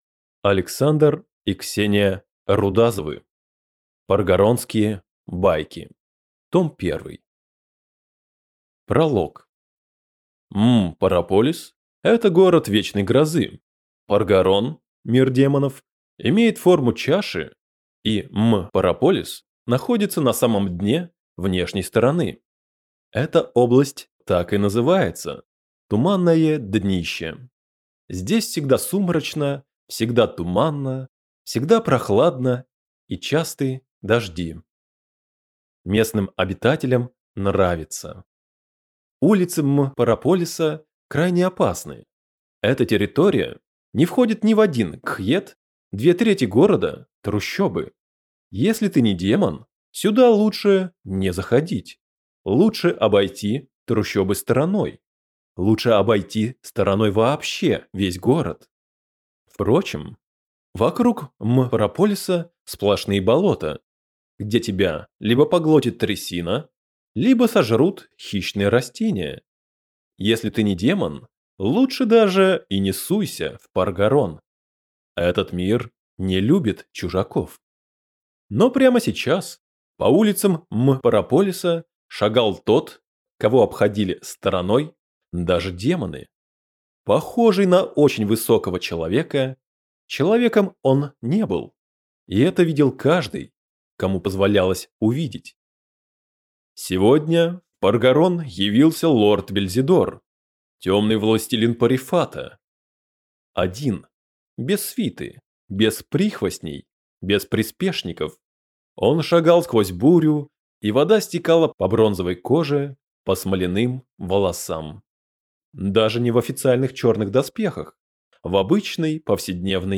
Аудиокнига Паргоронские байки. Том 1 | Библиотека аудиокниг